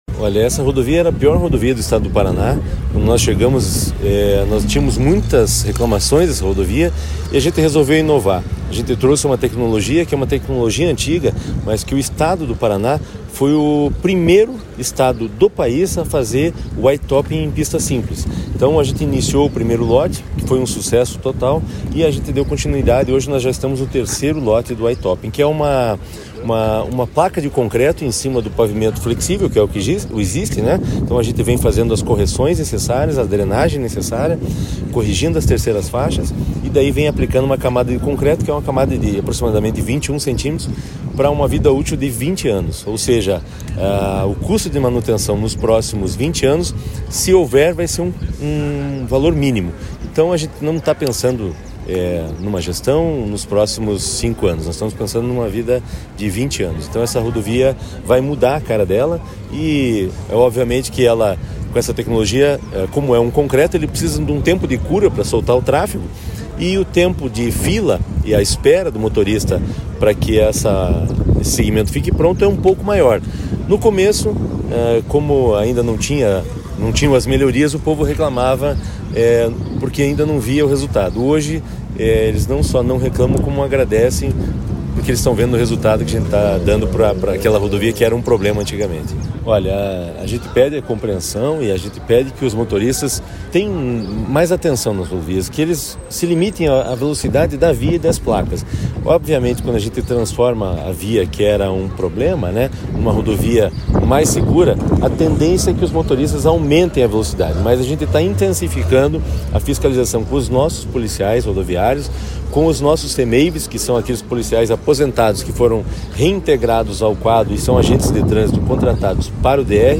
Sonora do diretor-presidente do DER, Fernando Furiatti, sobre a inauguração do segundo trecho da PRC-280 entre Palmas e Clevelândia